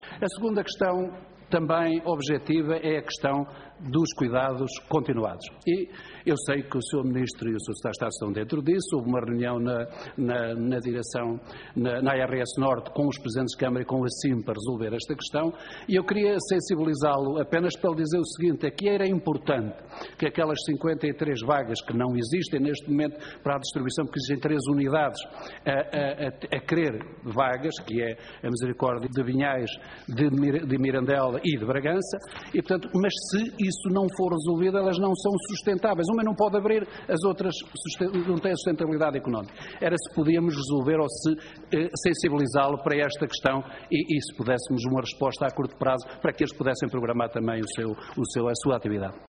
José Silvano, em declarações captadas pelo canal do Parlamento, pede um despacho semelhante ao dado ao Centro Hospitalar do Algarve, que aprove a mobilidade de profissionais para suprir eventuais carências.